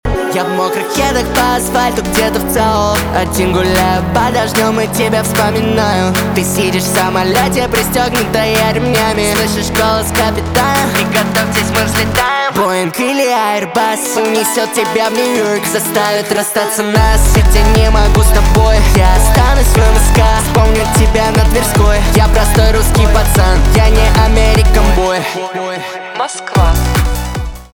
поп
битовые , качающие